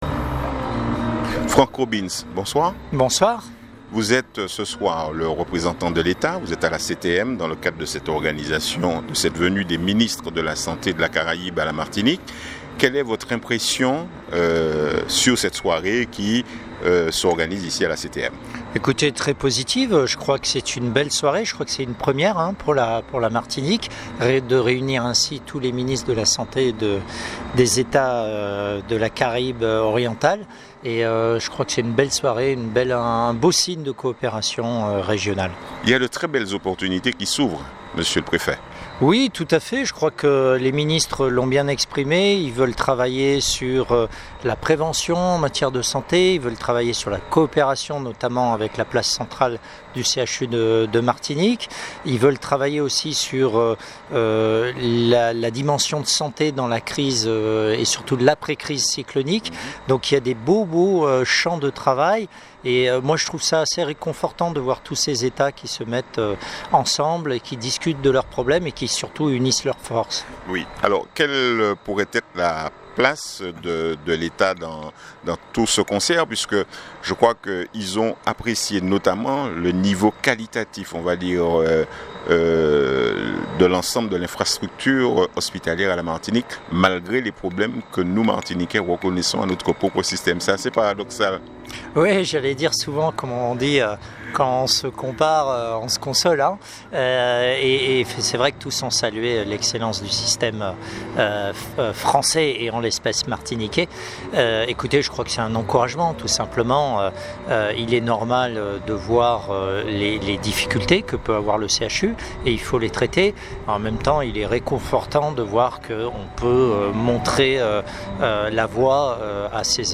Concernant l’OECS, Photo CTM Je vous propose d’écouter l’avis de Franck ROBINE Préfet de MARTINIQUE.